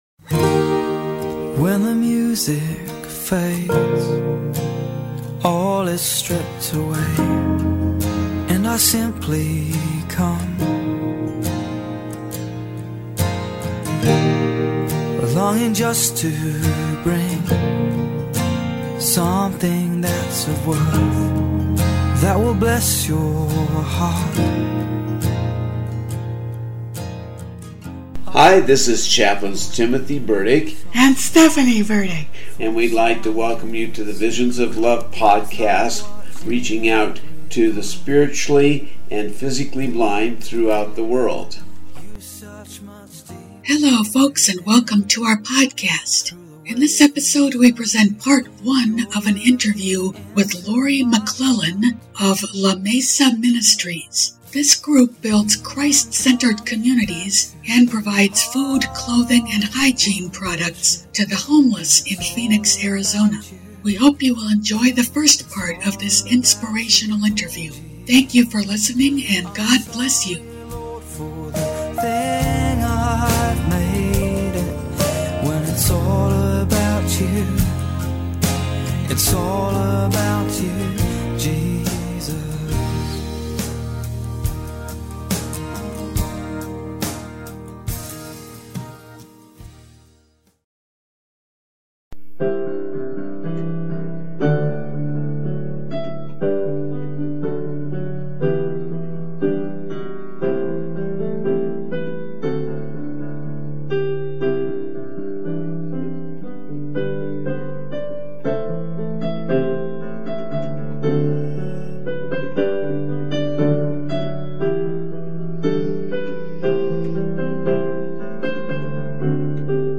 This group builds Christ-centered communities and provides food, clothing and other donated items to homeless people in Phoenix, AZ. We hope you'll enjoy the first part of this inspirational interview, and our keyboard and flute music which bookends it.